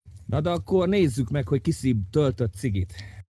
levelup.mp3